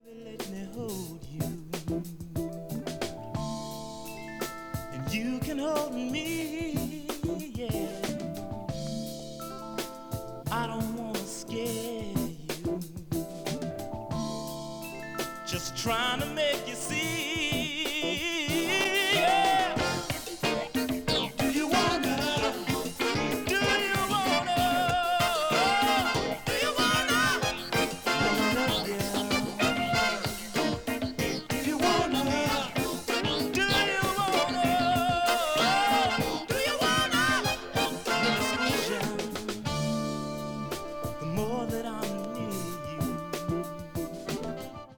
Funk / Soul